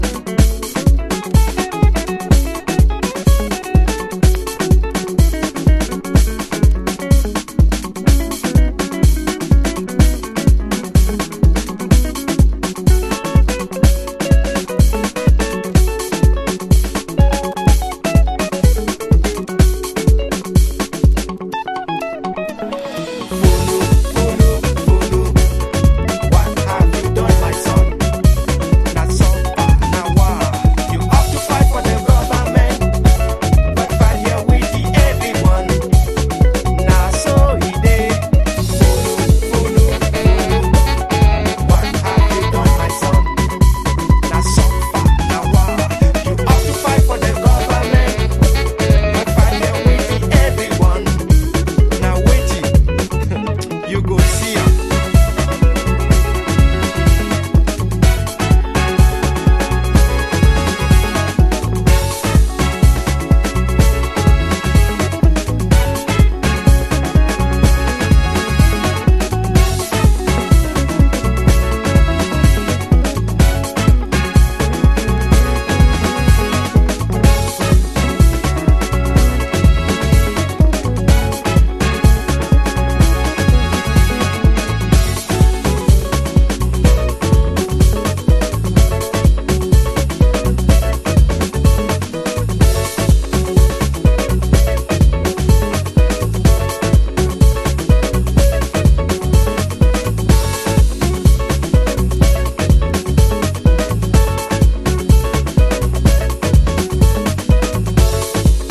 Early House / 90's Techno
熱く軽快に。AサイドはハイライフMIX、Bサイドはカメルーン・ギター・ダブ。